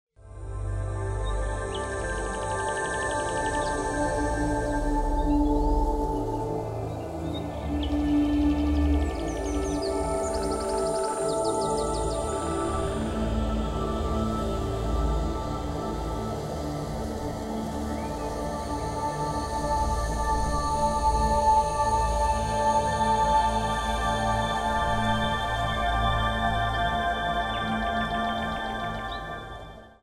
Isochronic Tones Brainwave Entrainment
meditation music,
new age music,
relaxation music,
binaural beats,